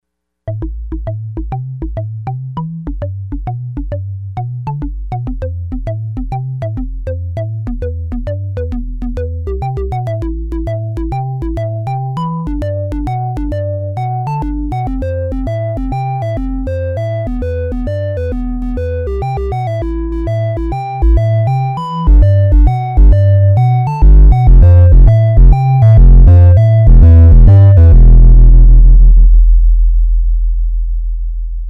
MP3 sample sounds (raw – no external processing)
subbass9.mp3